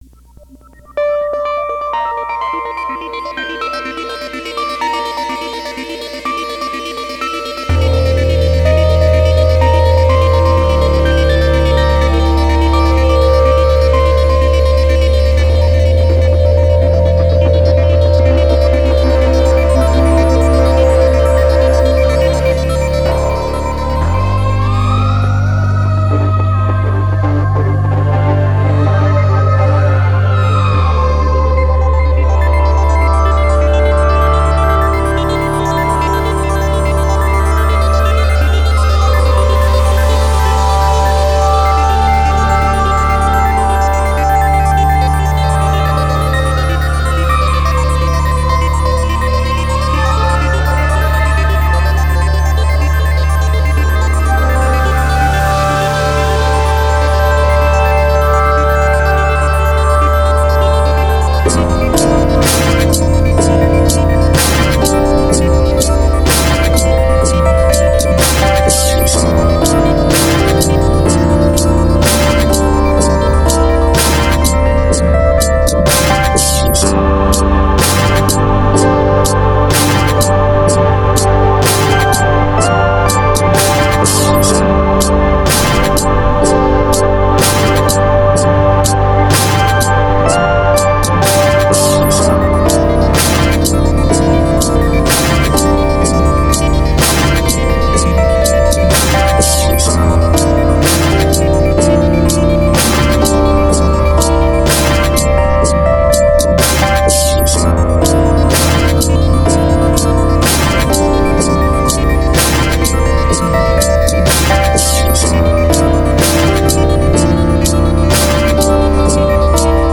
2013 play_arrow they say that if you're eaten during a dream, you'll never dream again. (sorry about the weird screamy sounds in the background. most of those weren't supposed to be there. I think there's a stuck note somewhere or something idk whatever sorry) (also full disclosure: I used this OHC to extend an old sketch of mine.